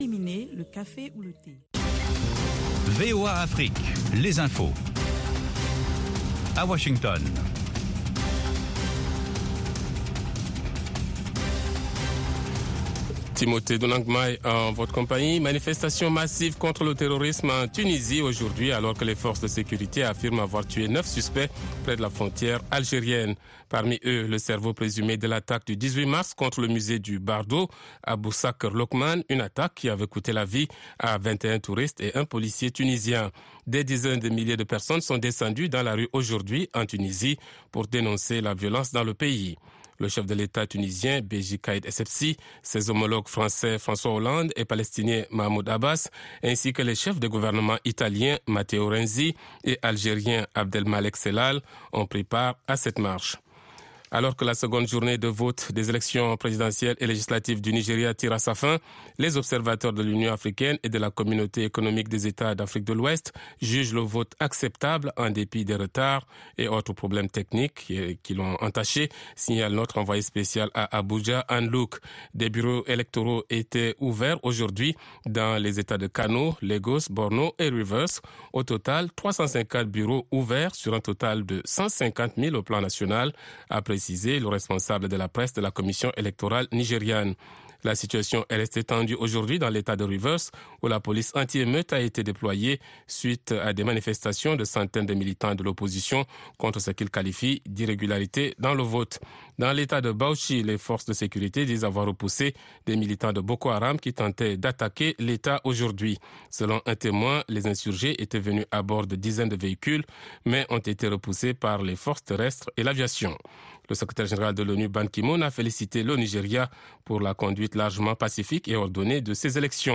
Bulletin
5 Min Newscast